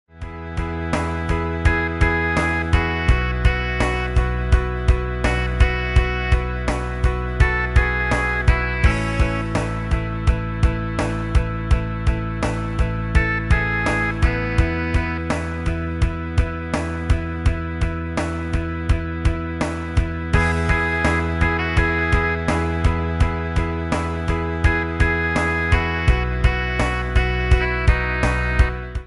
Tono de llamada